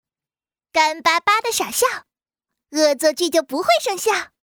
女声
正太-哪吒